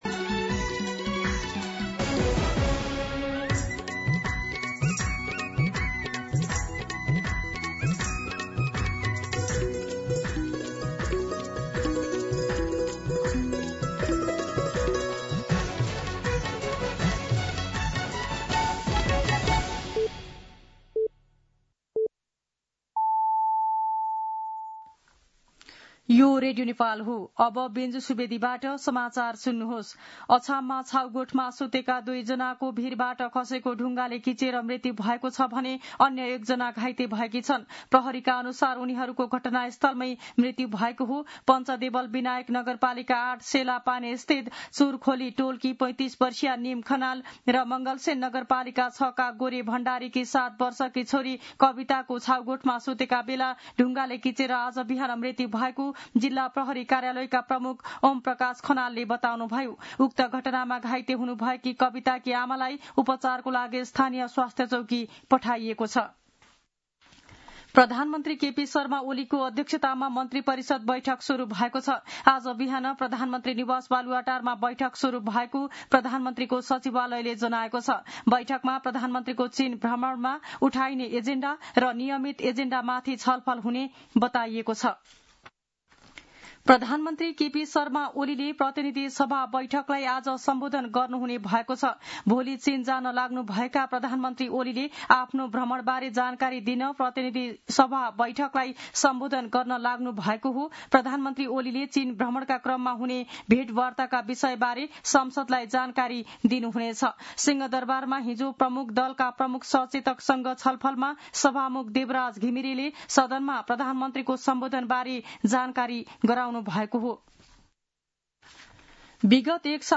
मध्यान्ह १२ बजेको नेपाली समाचार : १३ भदौ , २०८२